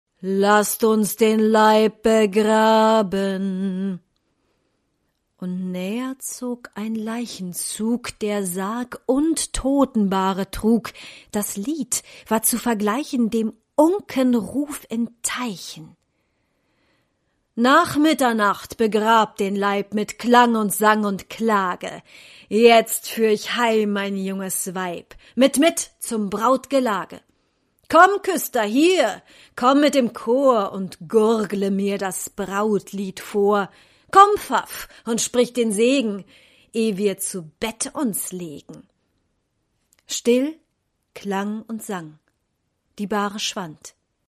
Reisebericht
Sprachproben